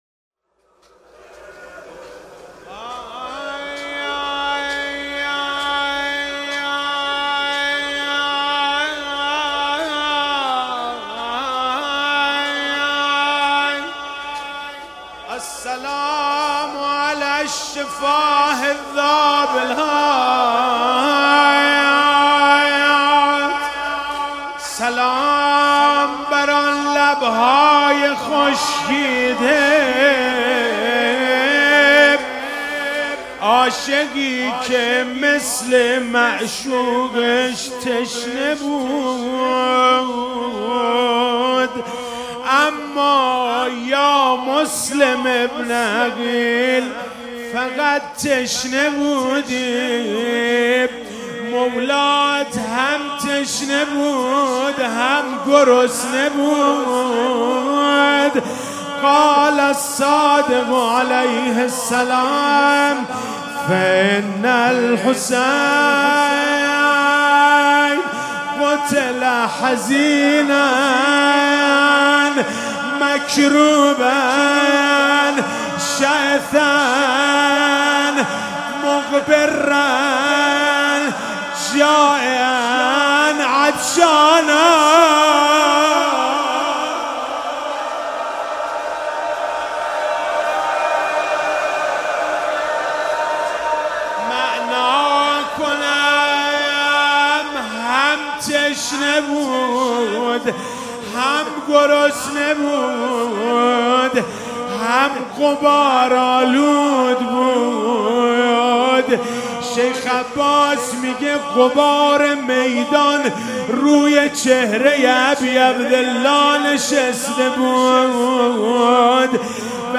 روضه
مداحی شب اول محرم همه مداحان